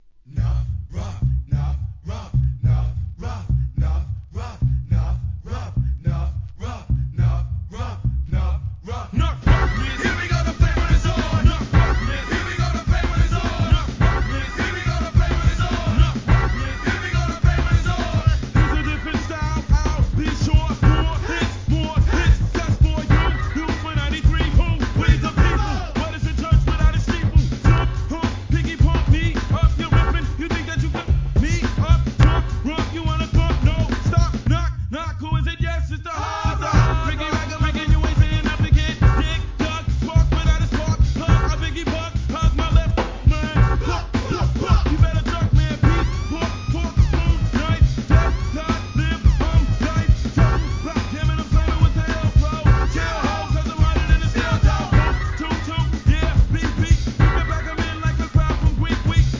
HIP HOP/R&B
王道ミドルスクールなノリの1993年マイナー盤!